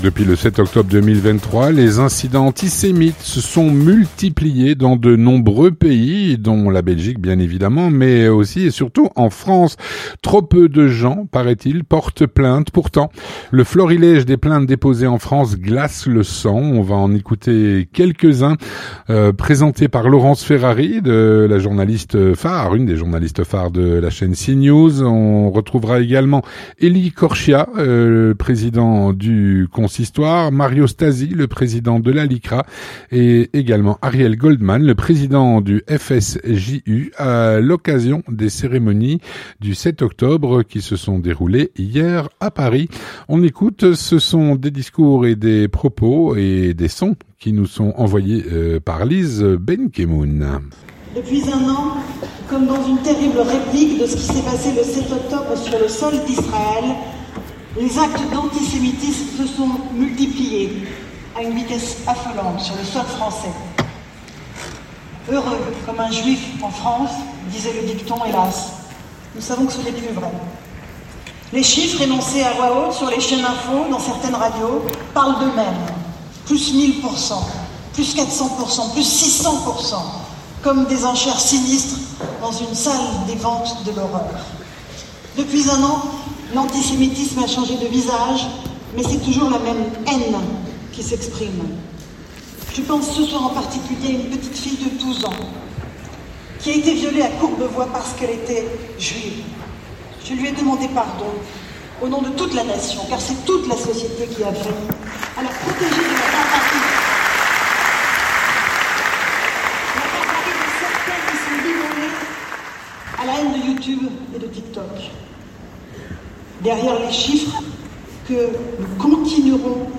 à l’occasion des cérémonies du 7 octobre à Paris.